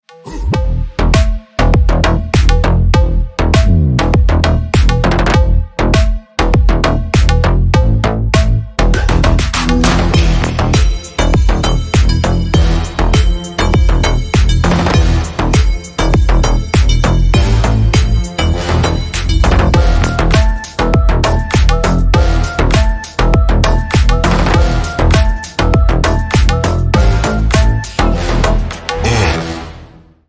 • Категория: Рингтоны